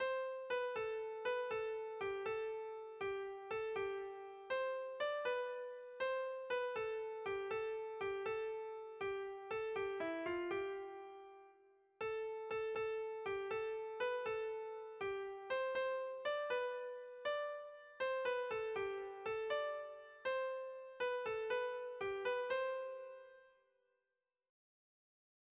Sentimenduzkoa
Zortziko txikia (hg) / Lau puntuko txikia (ip)
A-A2-B-A3